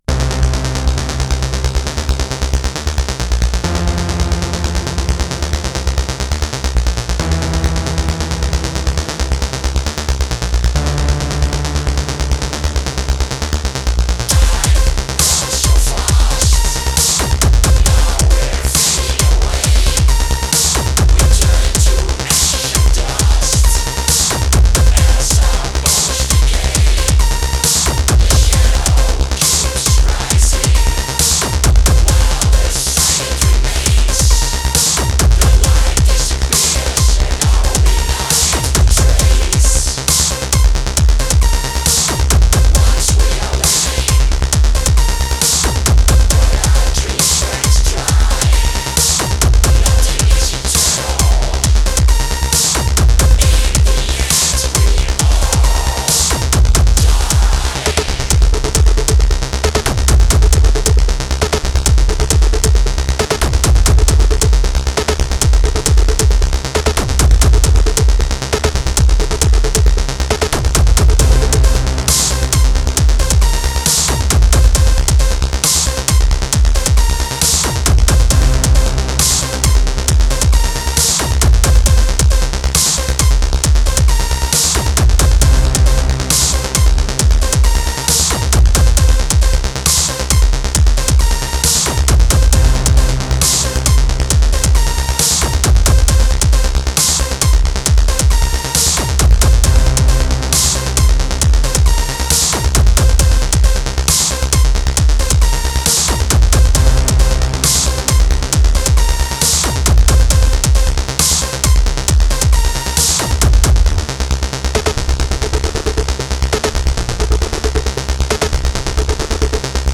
Dark Electro, Aggrotech